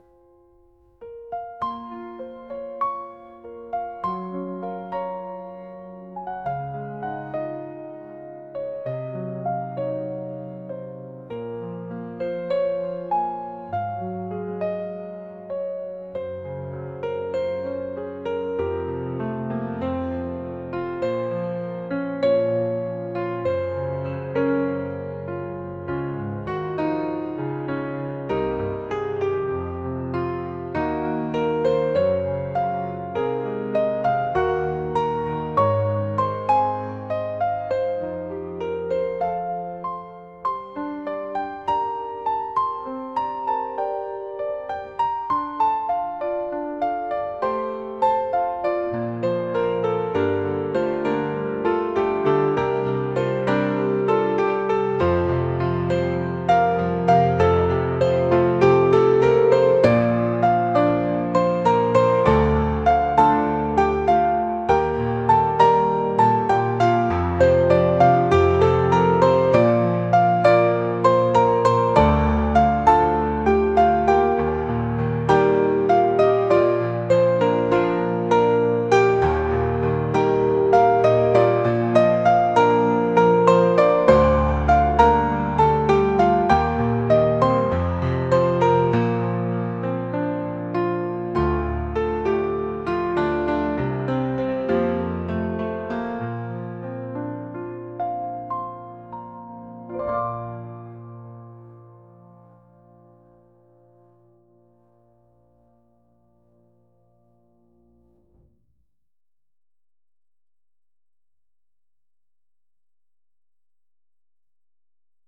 pop | acoustic